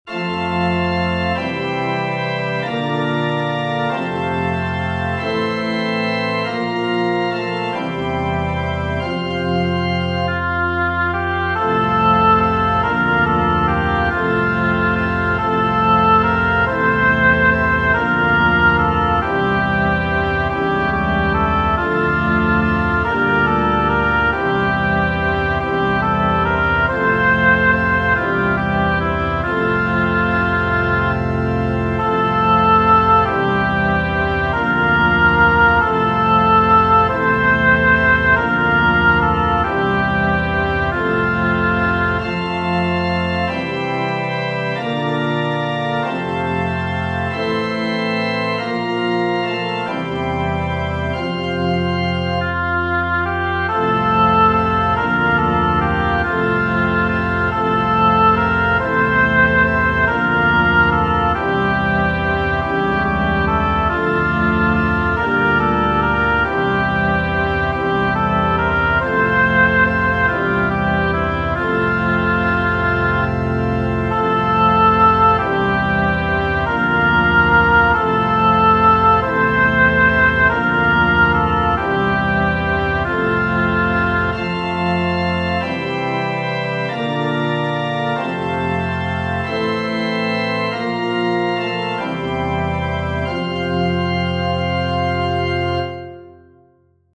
Genere: Religiose Canto mariano